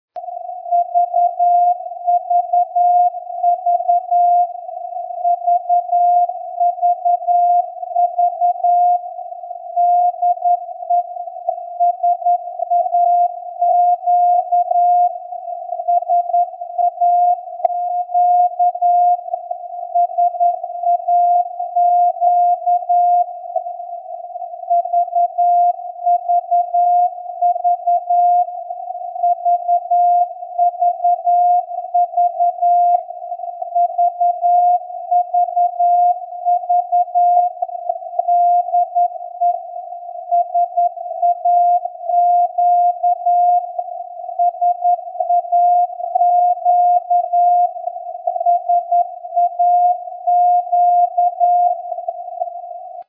This is an excerpt of the audio received